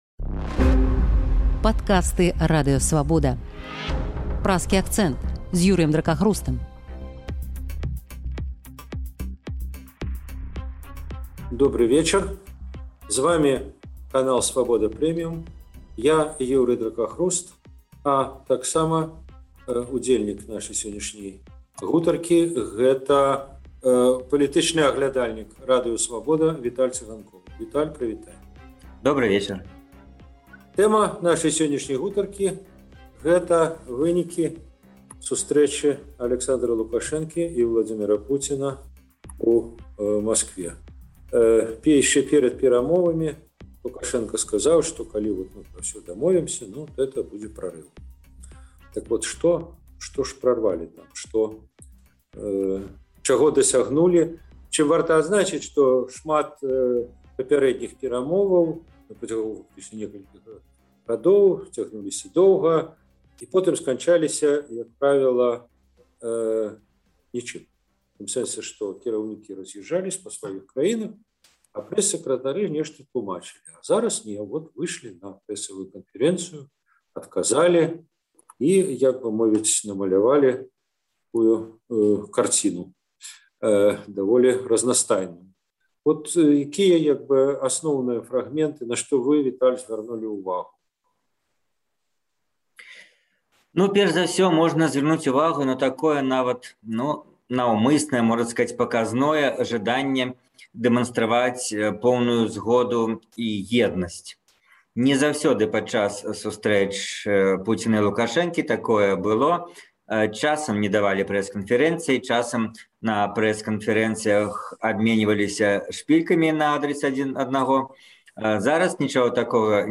Гэтыя пытаньні абмяркоўваюць палітычныя аглядальнікі